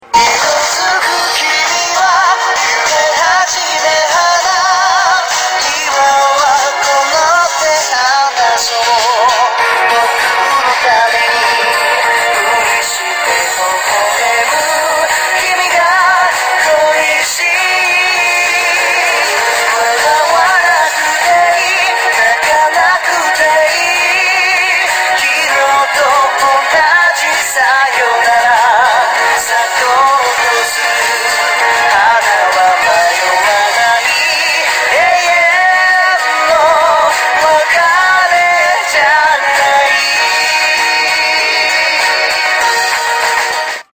완전 이번 싱글은 애수로 밀기로 했는모양.